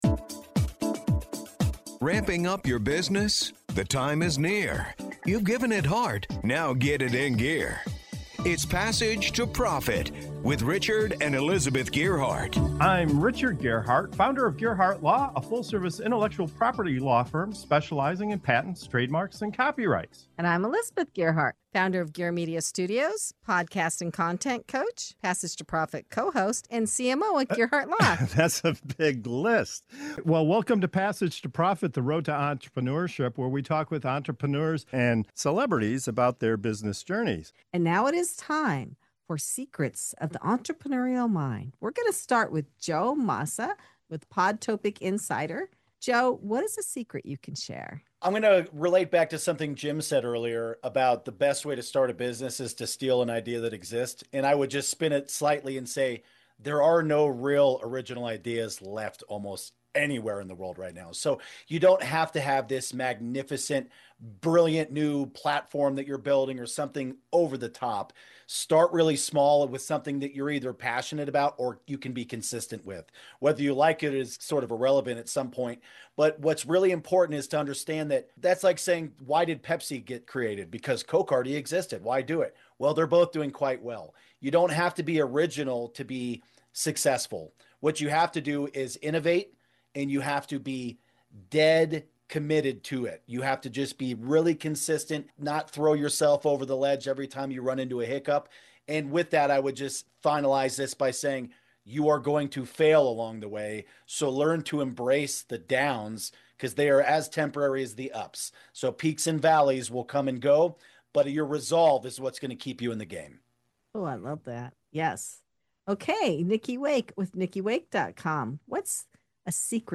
In this powerful segment of “Secrets of the Entrepreneurial Mind” on Passage to Profit Show, seasoned founders reveal what really drives long-term success—and it’s not originality or overnight wins. From why stealing ideas and innovating execution beats chasing brilliance, to how asking for help can radically accelerate growth, this conversation strips entrepreneurship down to its core truths.